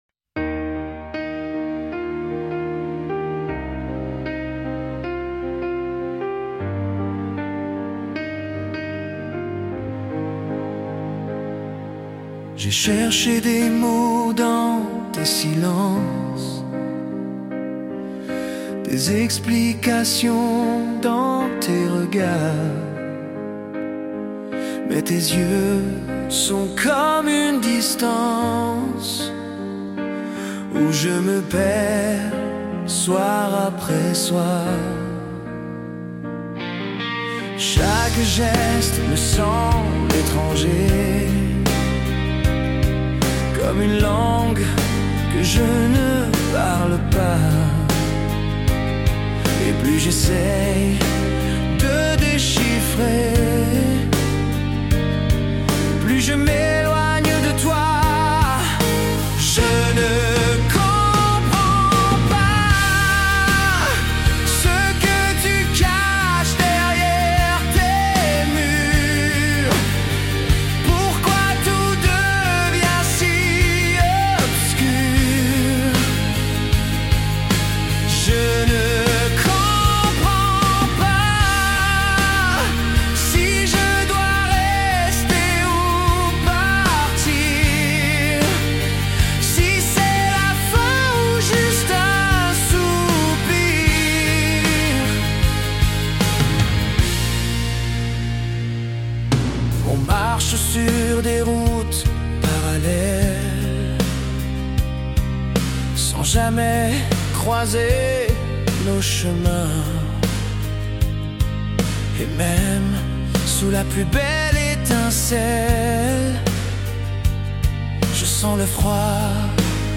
chanson française intense et introspective